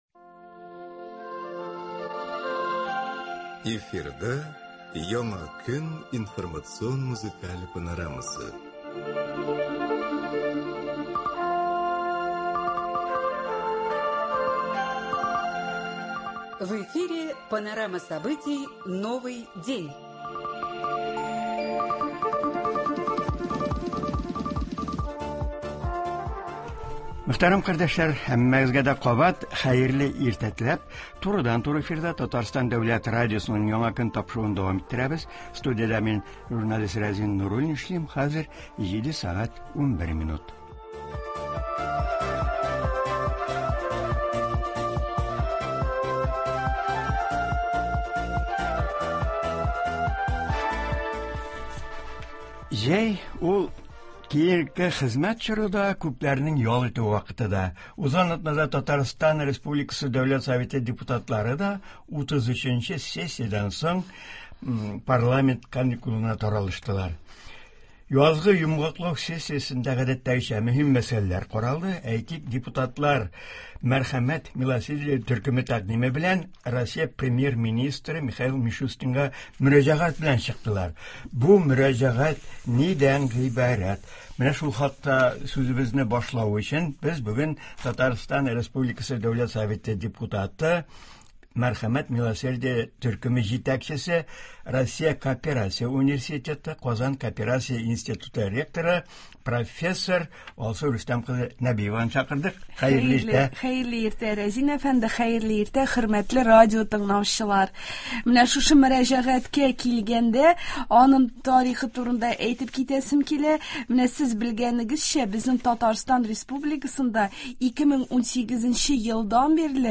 Туры эфир (18.07.2022)
Узган атнада Казанда Татарстан республикасы Дәүләт Советының 33 нче утырышы булды һәм анда хатын-кыз депутатларның “Мәрхәмәт”-“Милосердие” төркеме тәкъдиме белән Россия премьер-министры Михаил Мишустинга өченче бала тапкан хатын-кызларга ана капиталы бирү мәсьәләсен хәл итү үтенече белән мөрәҗәгать иттеләр. Болар хакында, шулай ук авыл җирләрендә кооперация оештыру мәсьәләләре хакында турыдан-туры эфирда Татарстан республикасы Дәүләт Советы депутаты, Мәскәү кооперация универитеты ректоры, профессор Алсу Рөстәм кызы Нәбиева сөйләячәк һәм тыңлаучылар сорауларына җавап бирәчәк.